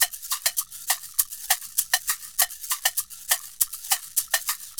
Caxixi_Samba 100_2.wav